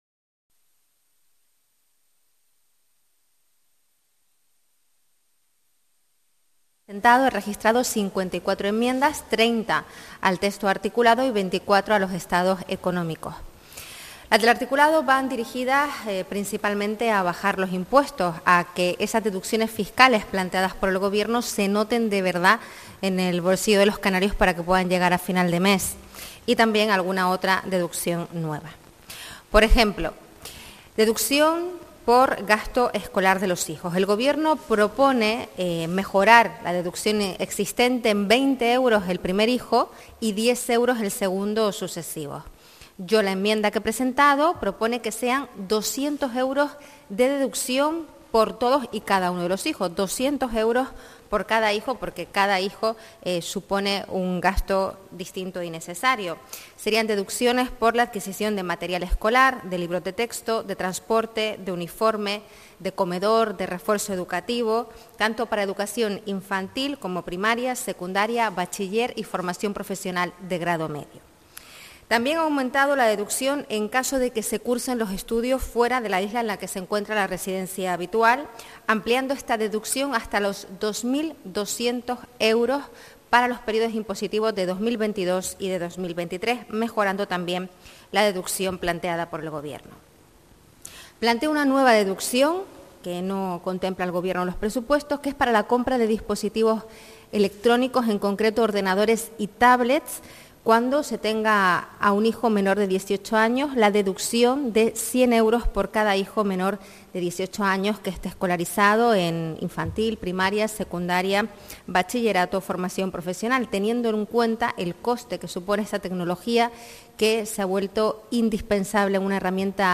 Rueda de prensa del GP Mixto para informar sobre las enmiendas presentadas a la ley de presupuestos - 09:30